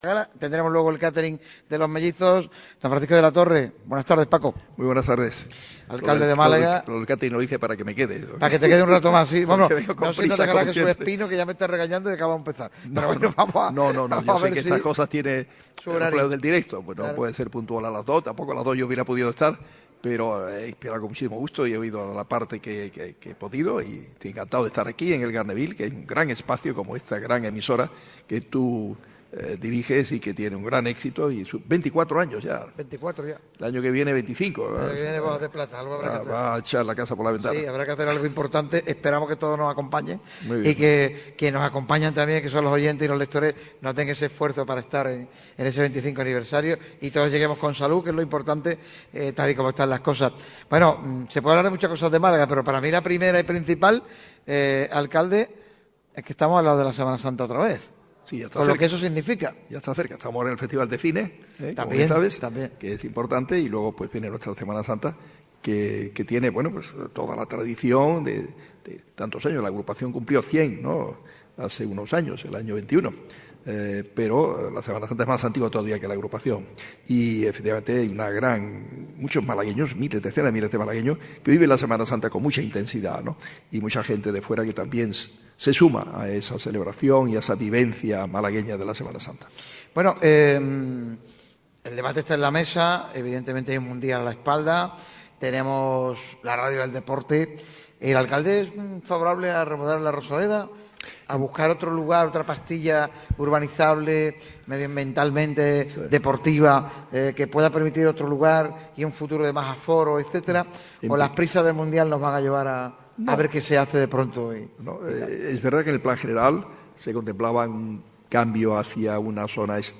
Francisco de la Torre, alcalde de Málaga ha sido uno de los protagonistas principales del programa XXIV aniversario de Radio MARCA Málaga. El edil habló sobre muchos temas de actualidad que tienen que ver con el Mundial de 2030 y el Málaga CF.